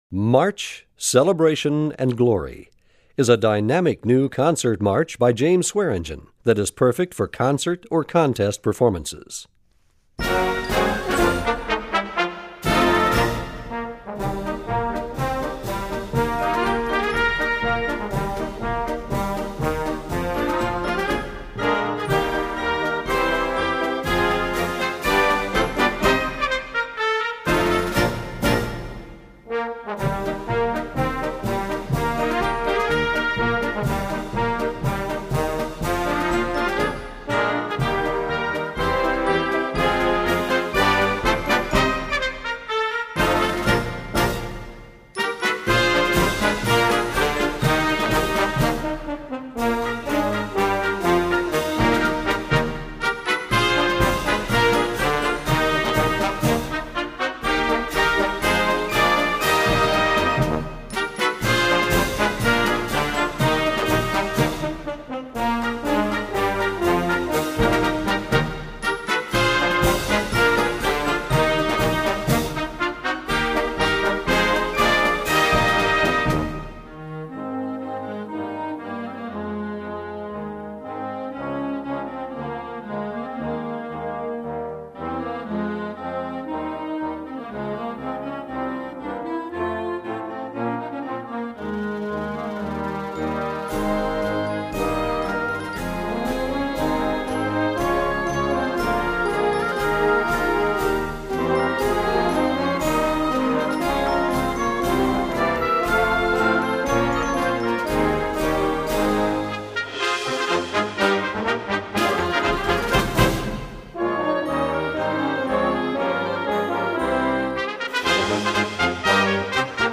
Gattung: Konzertmarsch
Besetzung: Blasorchester
Dieser spannende und dynamische Original-Konzertmarsch
Sehr temperamentvoll und sehr empfehlenswert!